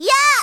SSBM-SFXV_ZS_ZELDA_FIRE.ogg.mp3